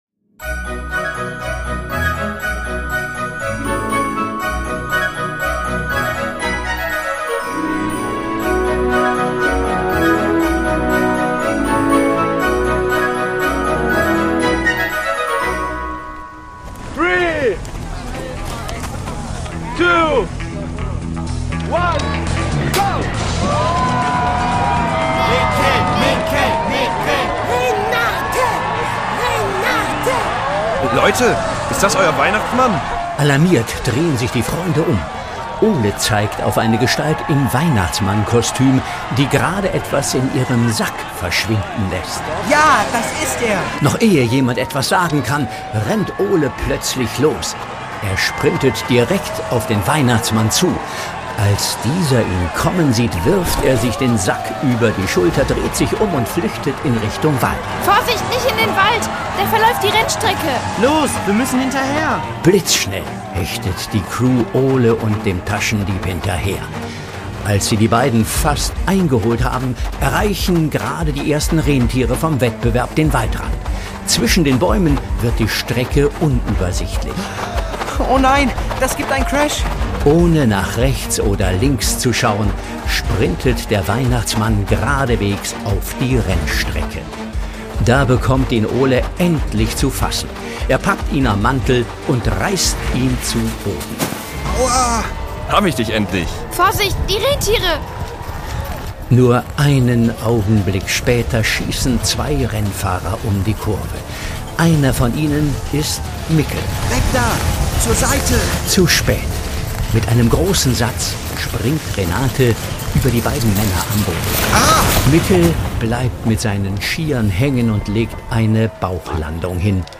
Lappland: Ein Rätsel im Schnee (24/24) | Die Doppeldecker Crew | Hörspiel für Kinder (Hörbuch)